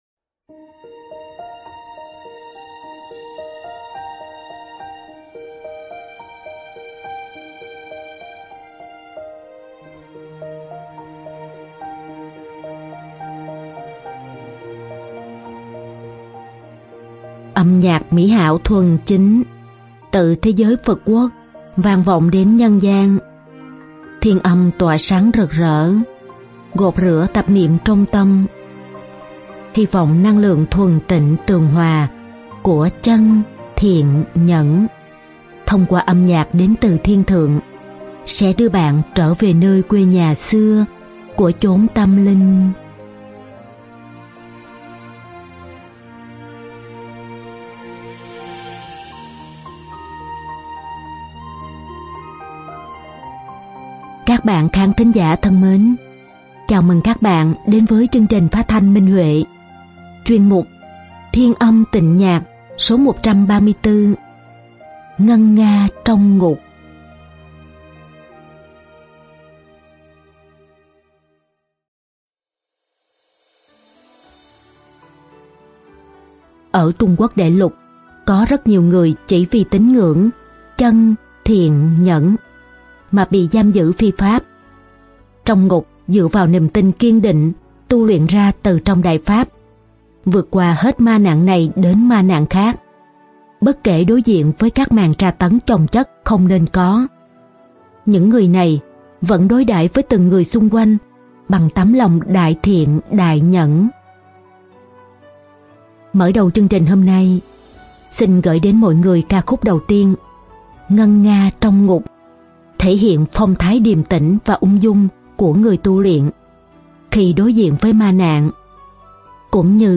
Âm nhạc mỹ hảo thuần chính, từ thế giới Phật quốc vang vọng đến nhân gian, thiên âm tỏa sáng rực rỡ, gột rửa tạp niệm trong tâm, hy vọng năng lượng thuần tịnh tường hòa của Chân-Thiện-Nhẫn, thông qua âm nhạc đến từ Thiên thượng, sẽ đưa bạn trở về nơi quê nhà xưa của chốn tâm linh.